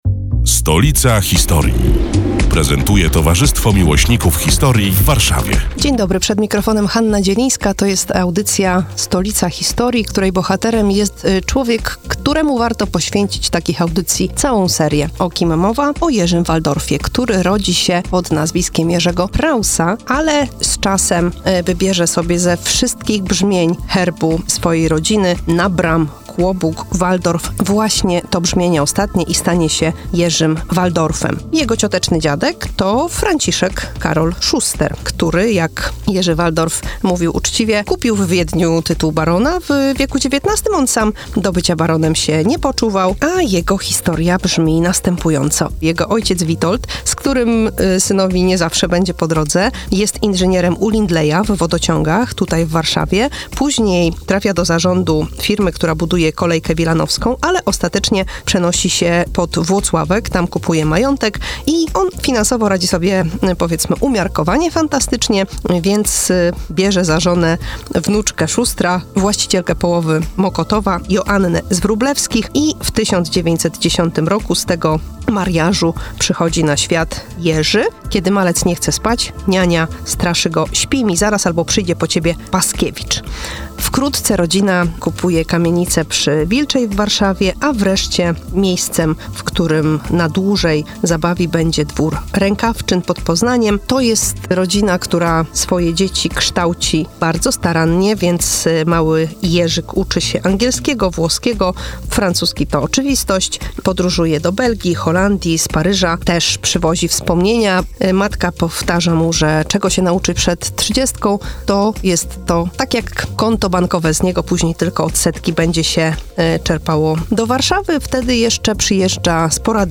Opowiada